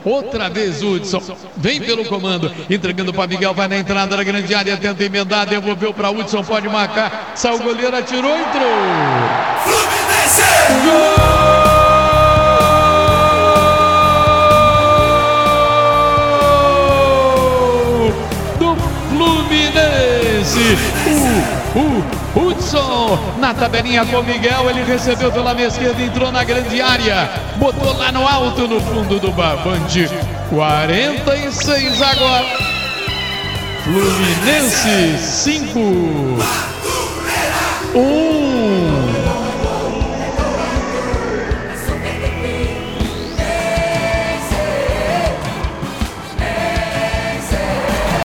Ouça os gols da goleada do Fluminense sobre o Madureira, na voz de José Carlos Araújo